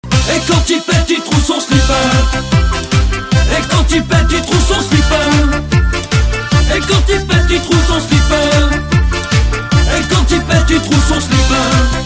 quand il pet il trou son slip Meme Sound Effect